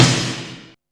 M SNARE 2.wav